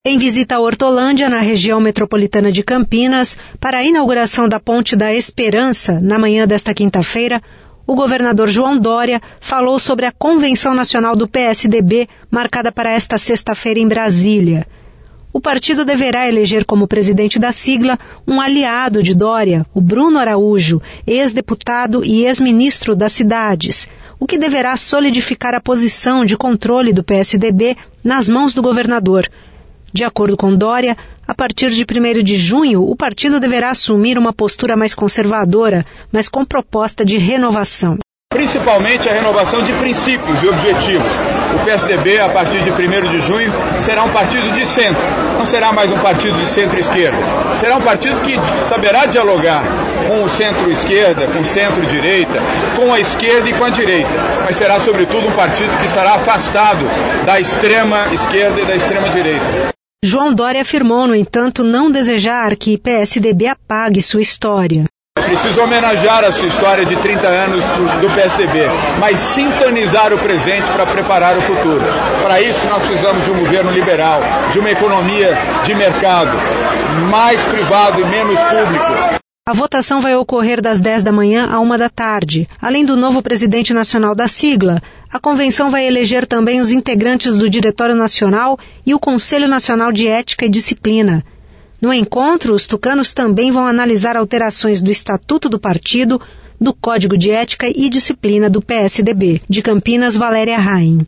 Em visita a Hortolândia, na Região Metropolitana de Campinas, para a inauguração da Ponte da Esperança, na manhã desta quinta-feira, o Governador João Doria falou sobre a convenção nacional do PSDB, marcada para esta sexta-feira, em Brasília.